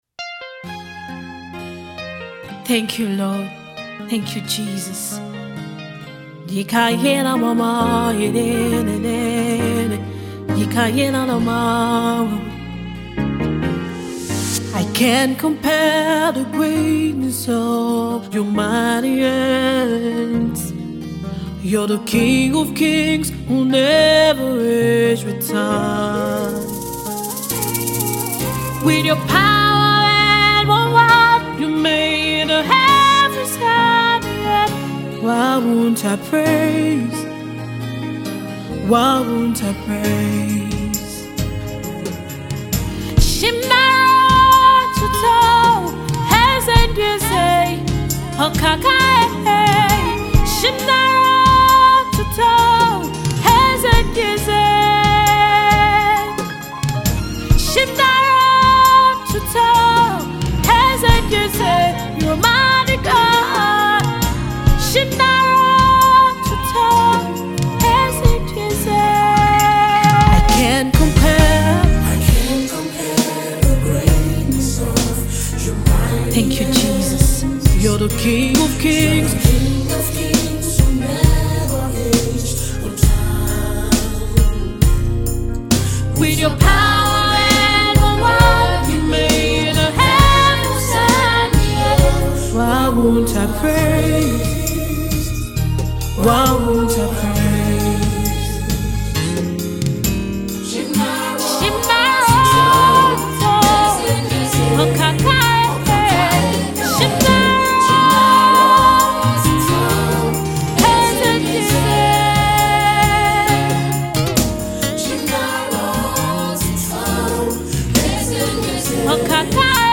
Inspirational Singer/Songwriter